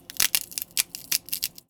R - Foley 49.wav